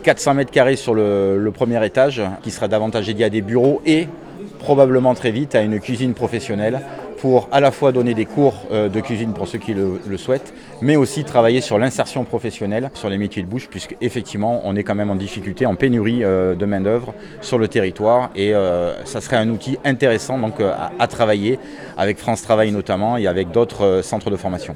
ITG Jean-Philippe Mas 2 – Halles gourmandes (25’’)